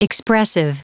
Prononciation du mot expressive en anglais (fichier audio)
Prononciation du mot : expressive